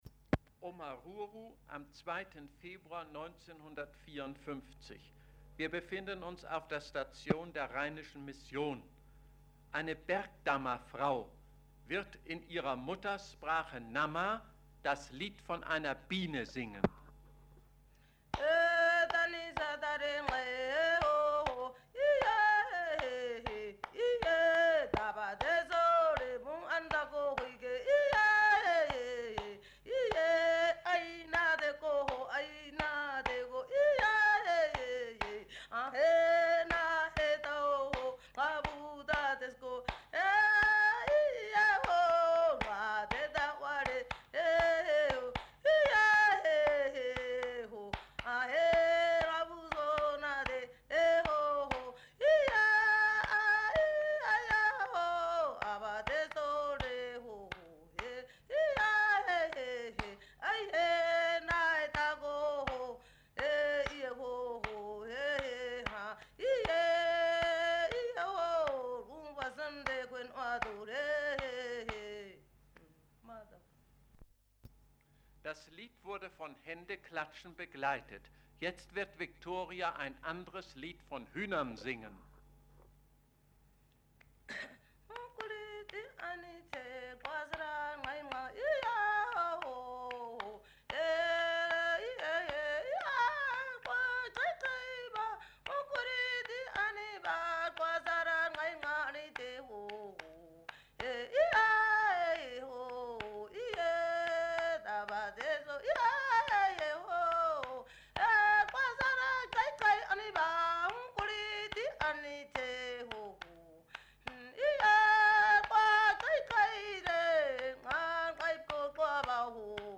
Recorded by German linguists in Namibia between 1953–1954, this collection consists of stories, songs, and historical narratives.
a ǂNūkhoe woman, performing two /gais or praise songs in Omaruru/!Huidi-ǁgams on 2 February 1954. In the first song, her strong vocal melody accompanied by rhythmic clapping speaks somewhat metaphorically of the challenges of harvesting honey (danib) from bees (!habun).
Nonetheless, the praise-song she sings, whose rhythmically syncopated musical form is known as /gais, remains remembered today.